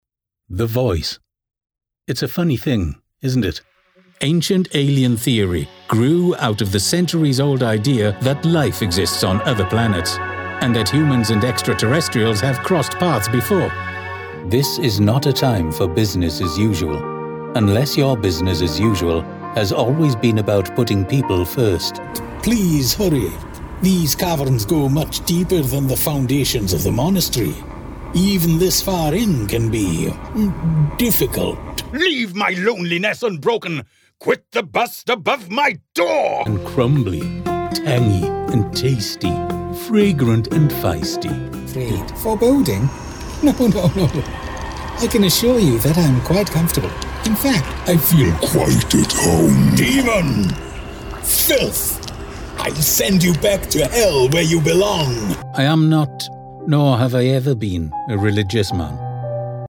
Inglés (Reino Unido)
Rode NT1-A, Synco D2, AKG D5, Red5 RV-8
Mediana edad
BarítonoBajoContraltoProfundoBajo